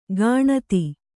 ♪ gāṇati